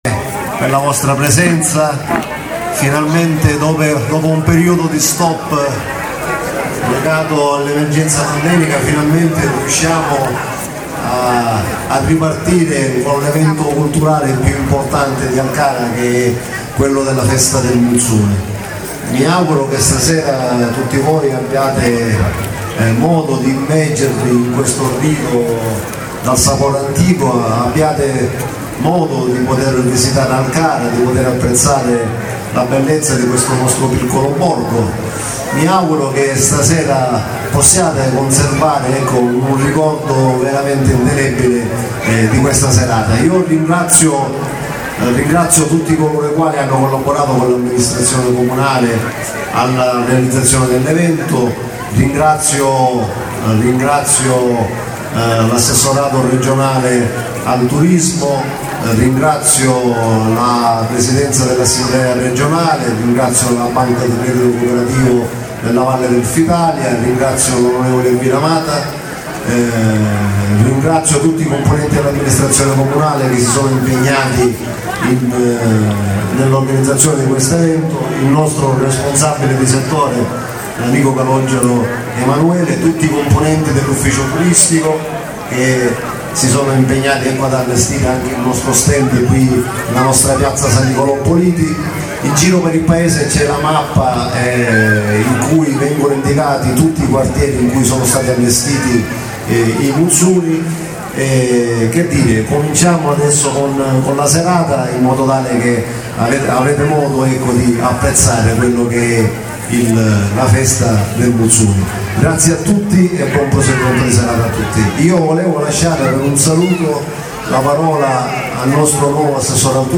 La ballata